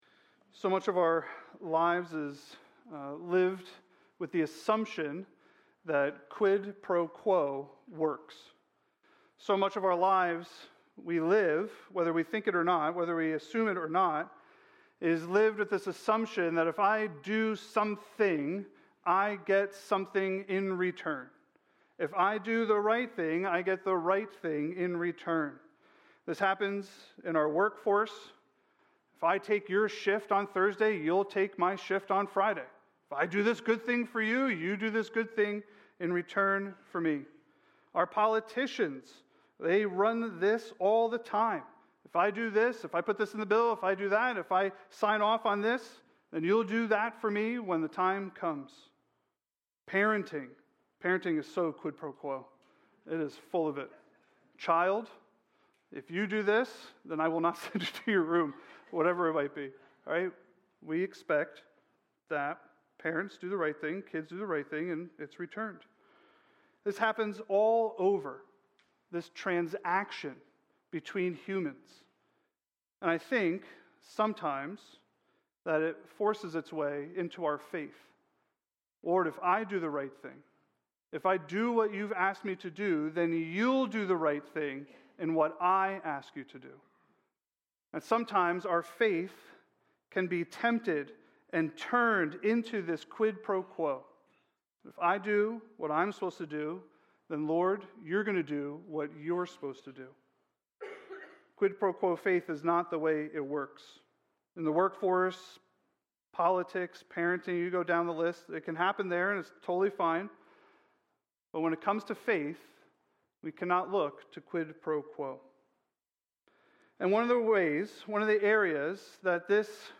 A message from the series "Psalms." In Psalm 44, we learned that the sufferer can cry out to God, for our hope is found in Him.